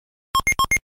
Sound effect from Super Mario Land